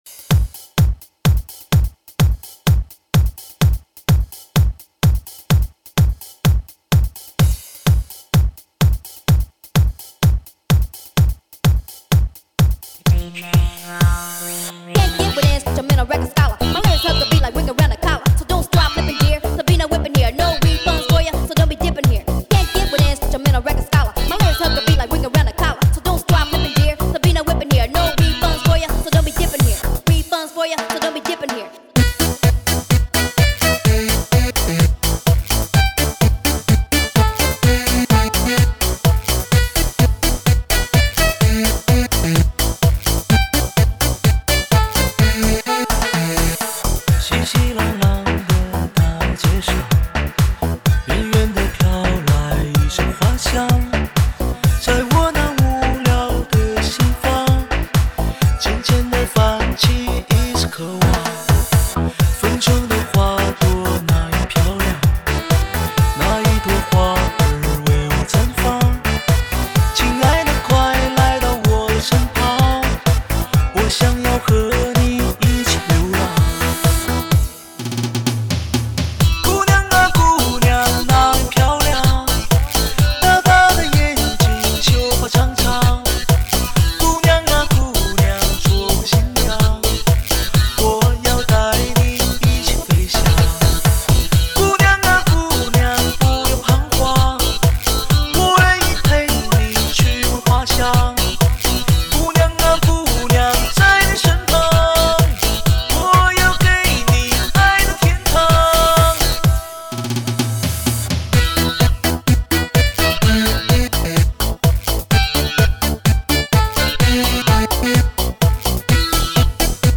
音质、音场、音效清晰震撼，新潮流行舞曲音乐元素，
加上欧美最迷幻时尚的舞曲，编配超强动感和节奏，
DJ打碟所带来的狂野快感，
肥厚的贝司节奏与DJ利落的刮碟声交织在一起，